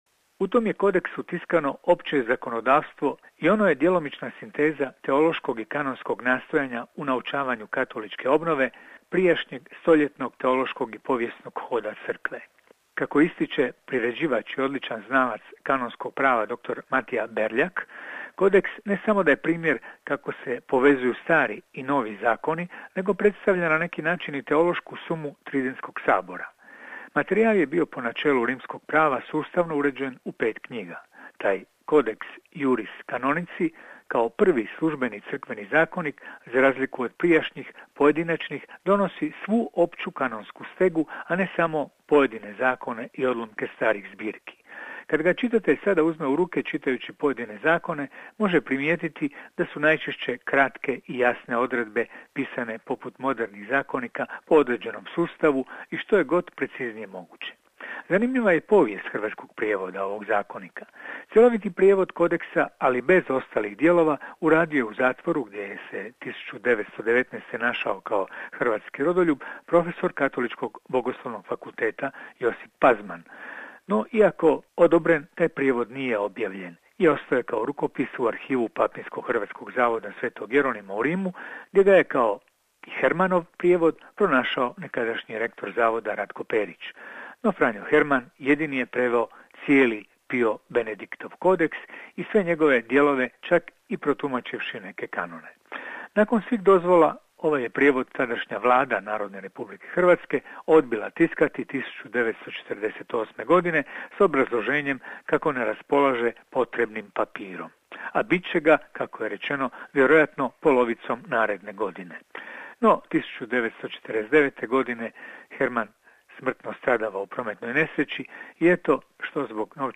Recenzija knjige